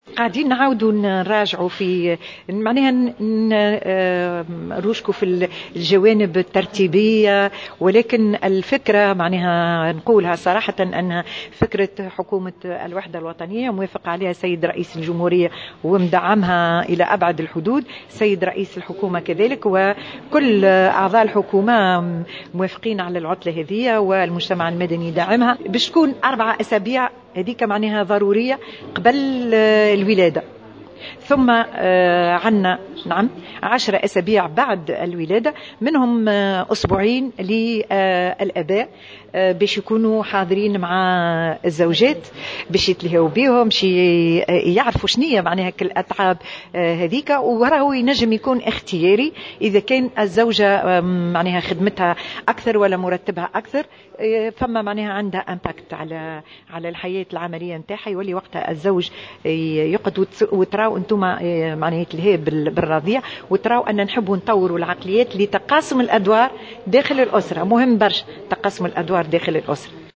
أكّدت وزيرة المرأة والأسرة والطفولة نزيهة العبيدي في تصريح لمراسلة الجوهرة اف ام، أن وزارتها بصدد العمل على الجانب الترتيبي للتمديد في عطلة الأمومة والأبوة.